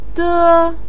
de1.mp3